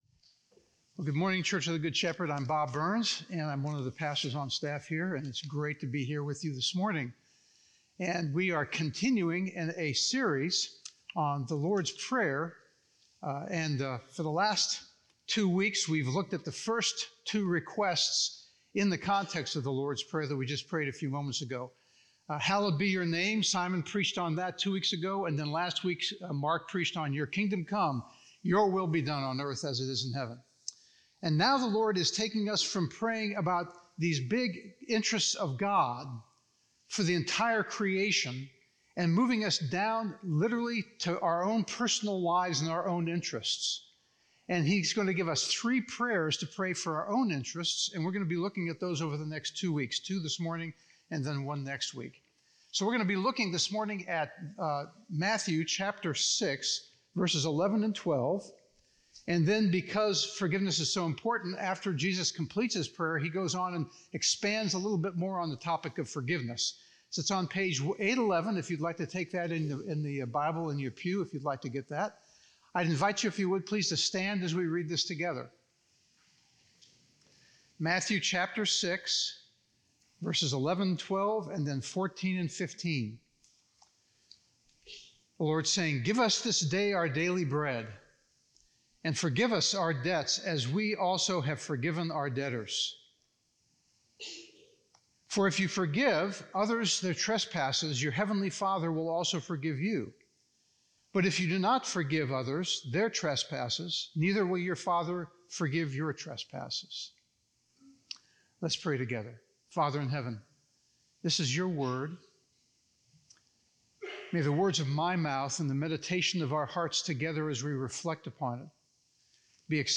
View this week’s sermon discussion questions .